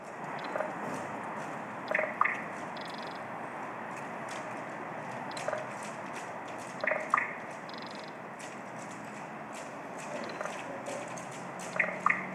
Ääntä Iiris-talon edestä